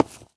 footstep_concrete_walk_14.wav